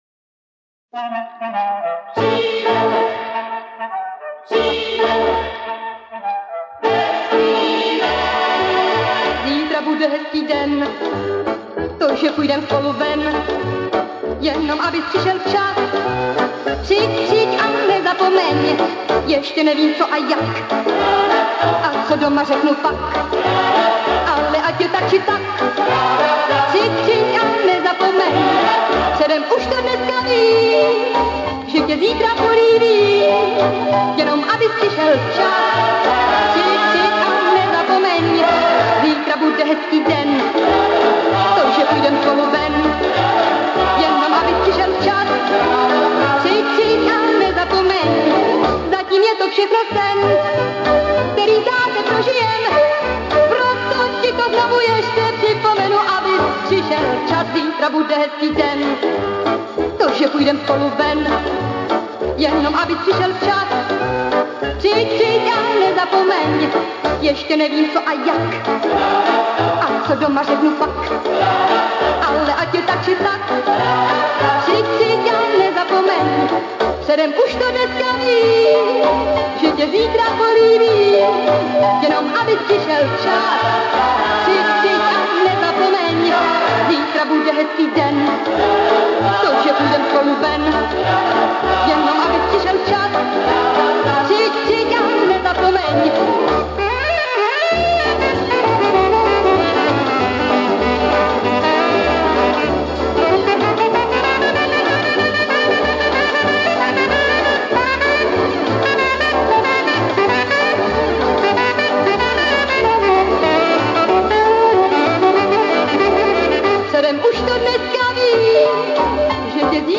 Записал 4 песни с пластиночки.